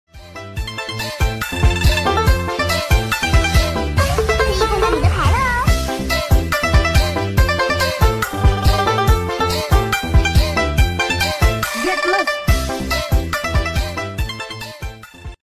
by be man sound rungkad Meme Sound Effect